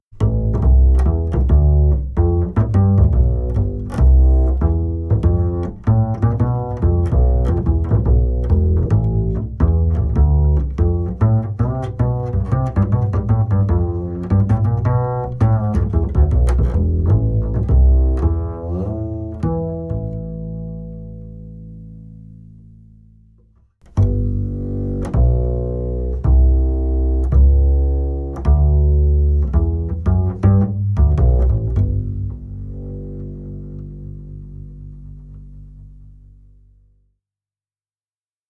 Contrebasse 3/4 Hongroise Erable flammé - Boris Giraud Music
Pizzicato